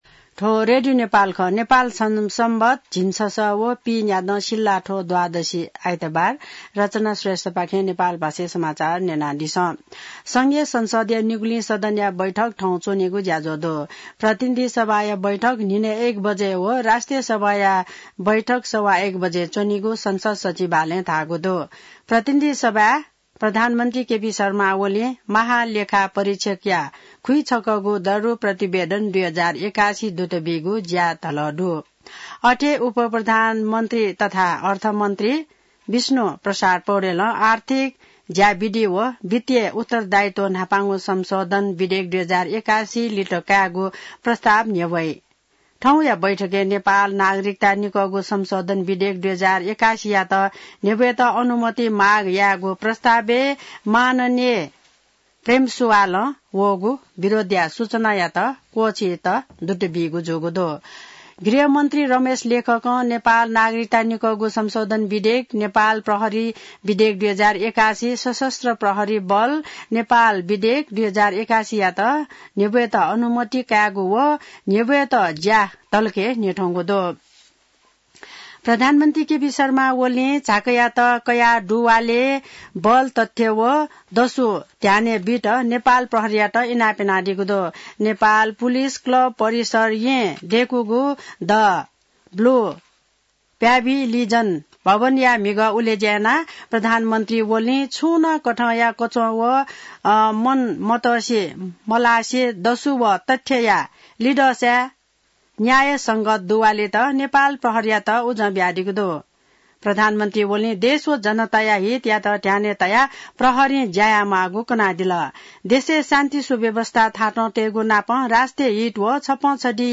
नेपाल भाषामा समाचार : २८ माघ , २०८१